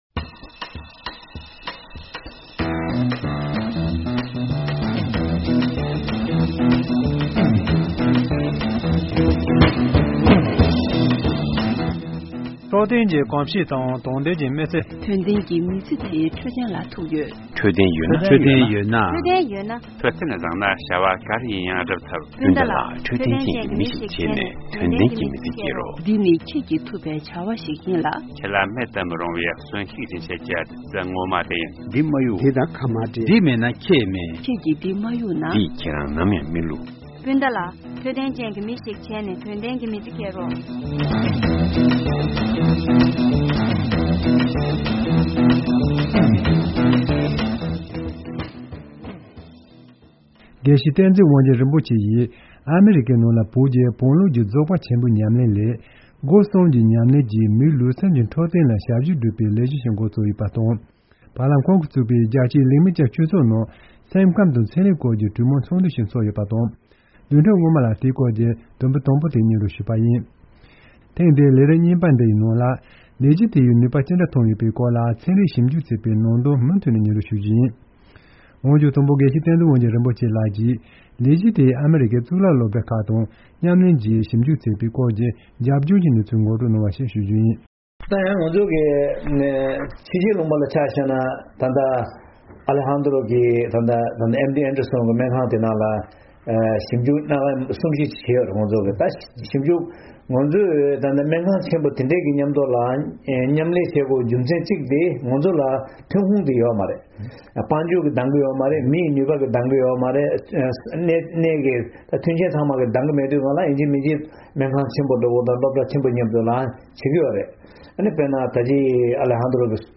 ཞིབ་འཇུག་པ་དང་འབྲེལ་ཡོད་མཁས་པ་ཁག་ལ་བཅའ་འདྲི་ཞུས་ཏེ་ཕྱོགས་བསྒྲིགས་ཞུས་པའི་གནས་ཚུལ་ཞིག་གསན་རོགས་གནང་།